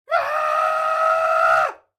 Minecraft Version Minecraft Version latest Latest Release | Latest Snapshot latest / assets / minecraft / sounds / mob / goat / screaming_pre_ram2.ogg Compare With Compare With Latest Release | Latest Snapshot
screaming_pre_ram2.ogg